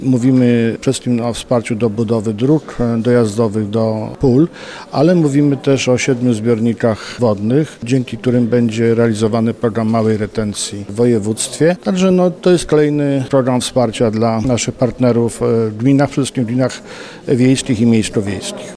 – W całym województwie mazowieckim dofinansowane zostaną zadania za blisko 30 milionów złotych – mówi Adam Struzik, marszałek Mazowsza.